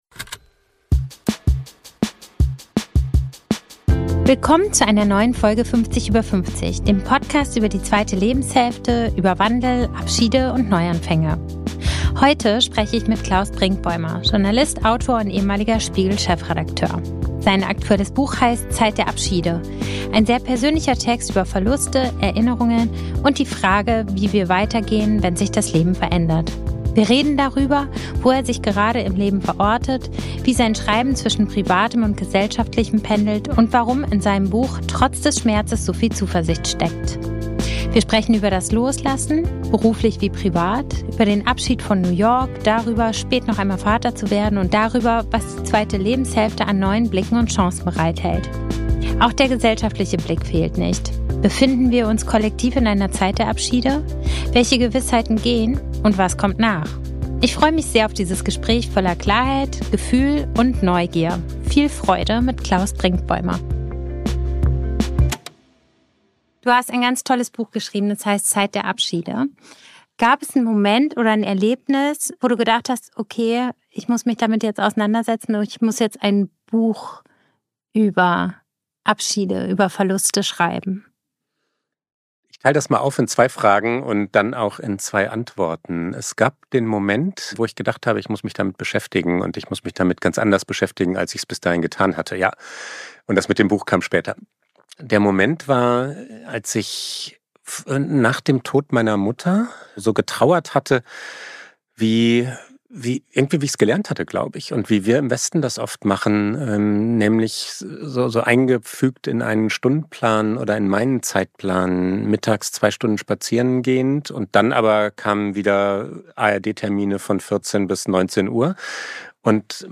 Heute spreche ich mit Klaus Brinkbäumer – Journalist, Autor und ehemaliger Spiegel-Chefredakteur.